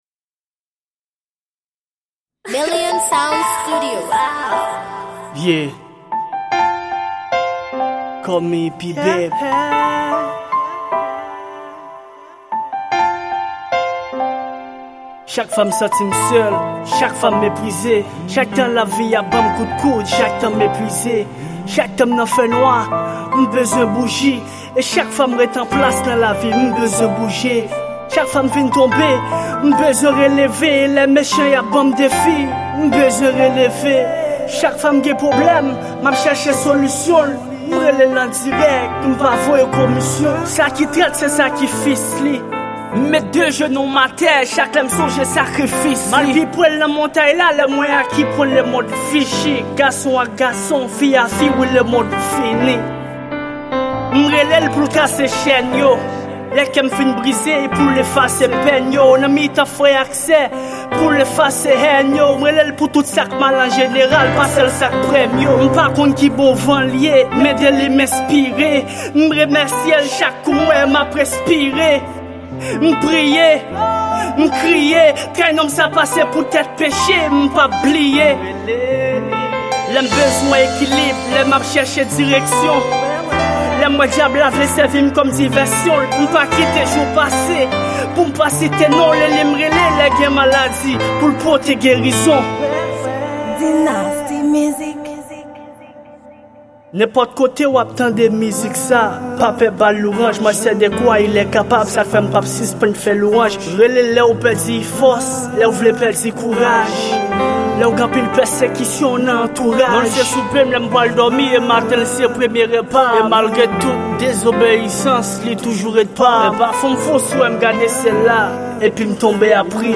Genre: Rap Gospel